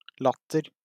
wymowa:
IPA/ˈladʌ/